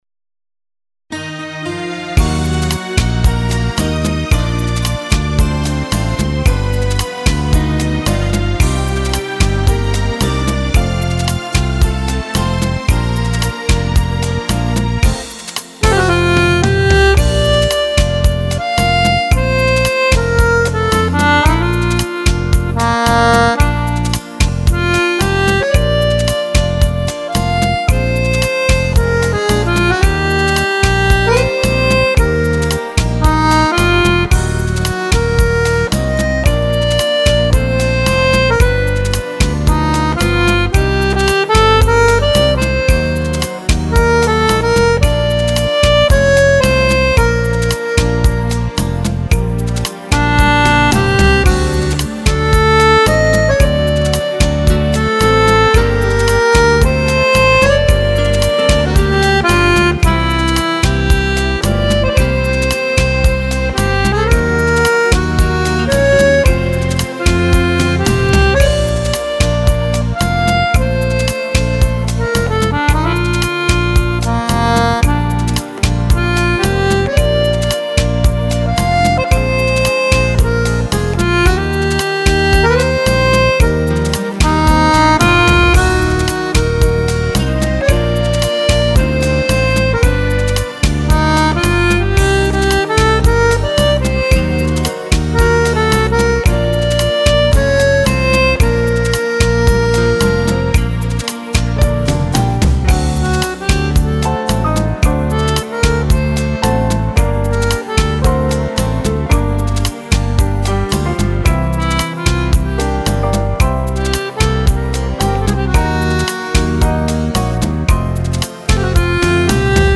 Rumba per Fisarmonica
Fisarmonica